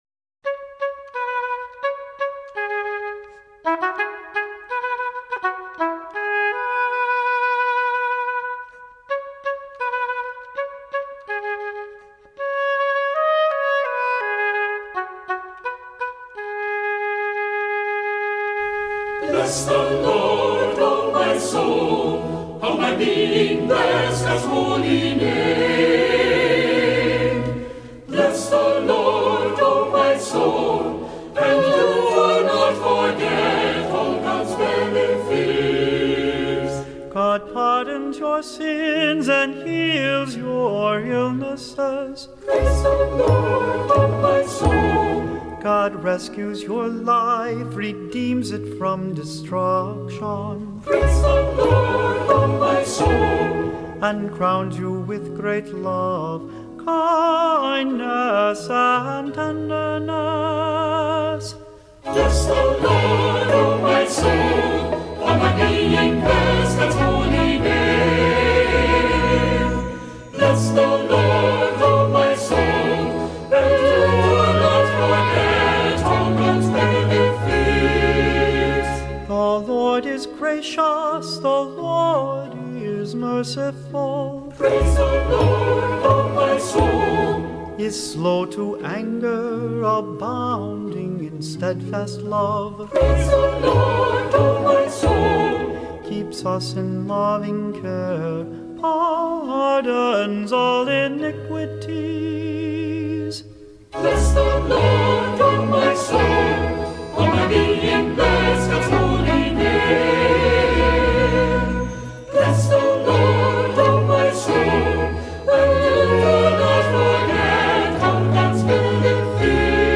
Voicing: Assembly,Cantor,SATB